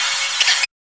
3004R SYN-FX.wav